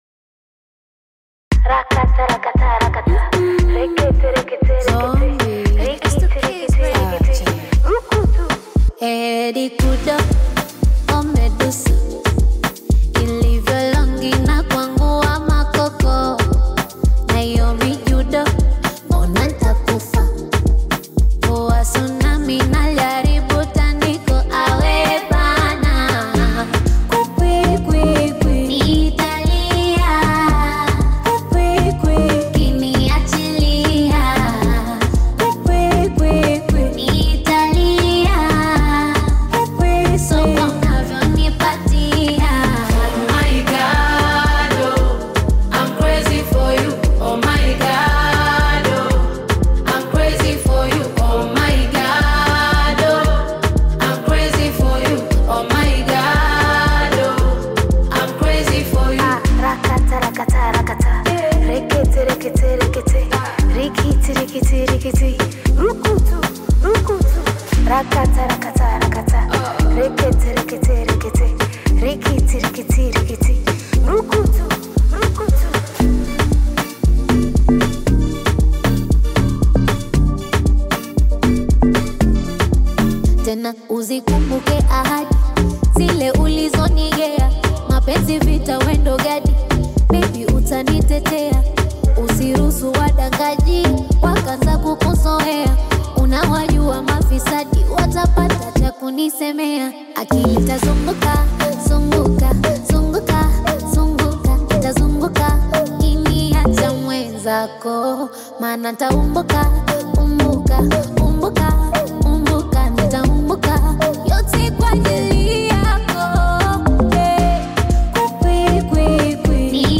Female artist
beautiful melodious track